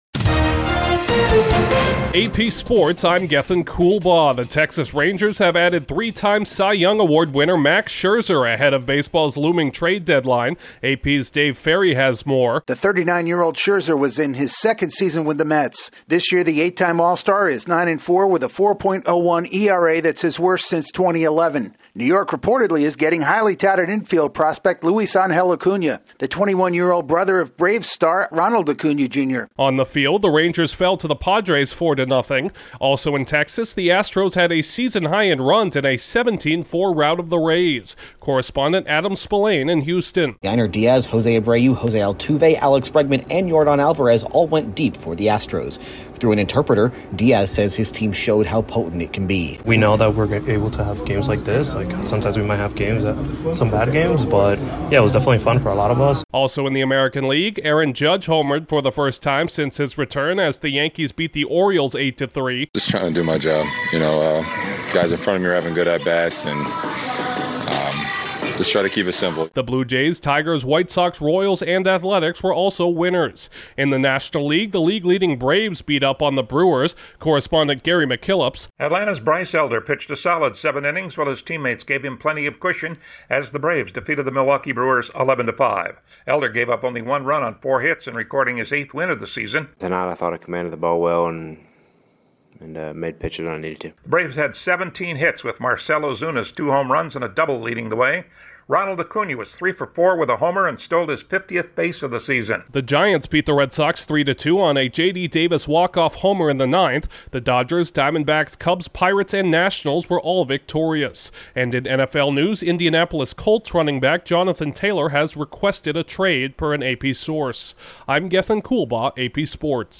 The Rangers land an ace after falling to the Padrest, the Astros clobber the Rays, the Judge renders a verdict for the Yankees, the Braves cruise past the Brewers and a Colt wants to bust out of his stall. Correspondent